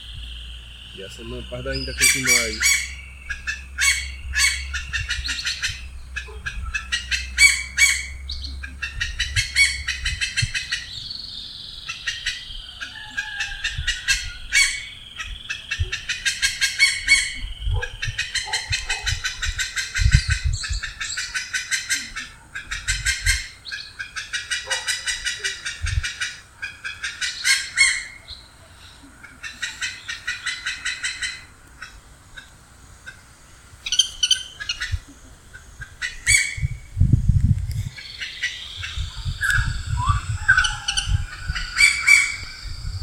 Grey-cowled Wood Rail (Aramides cajaneus)
Life Stage: Adult
Detailed location: Parque Municipal de Maceió
Condition: Wild
Certainty: Photographed, Recorded vocal